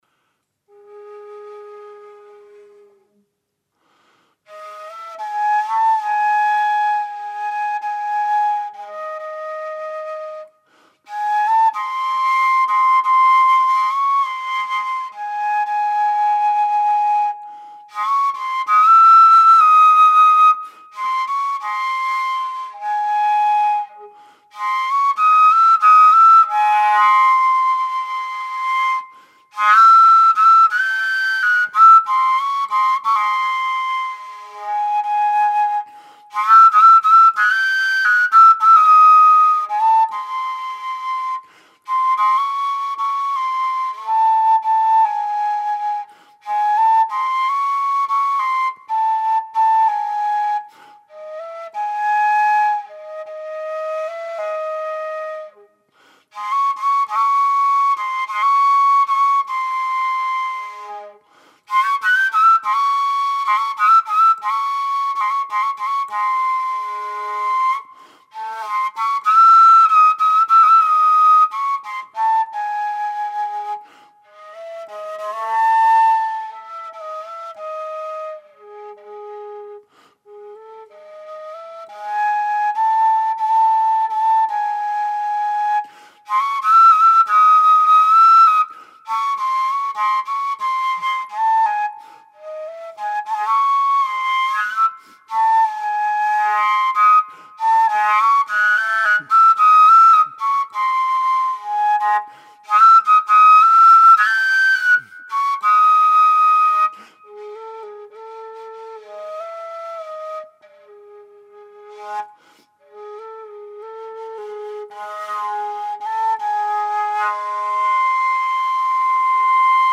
Harmonic Overtones Flutes
Listen to low Ab improvisation (:audioplayer